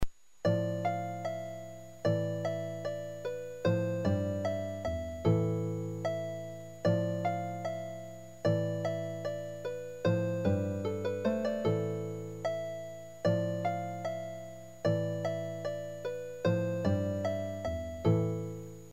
arpa.mp3